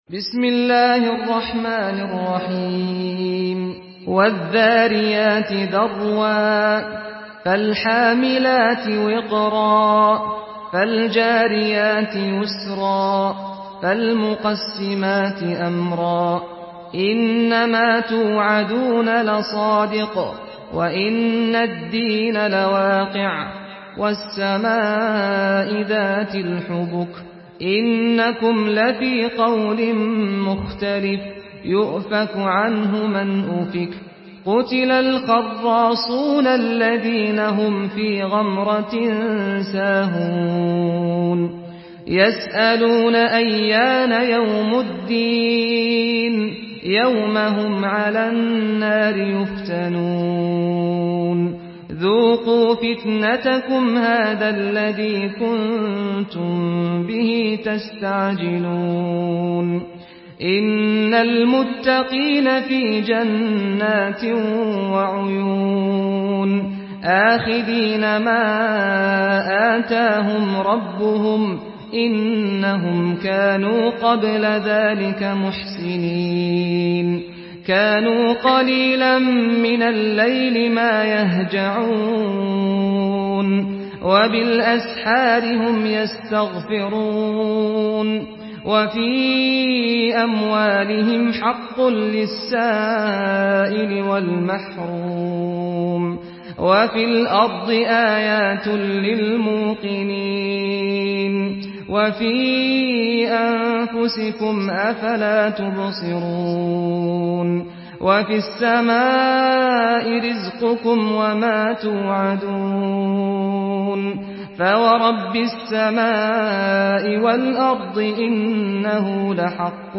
Surah Ad-Dariyat MP3 by Saad Al-Ghamdi in Hafs An Asim narration.
Murattal Hafs An Asim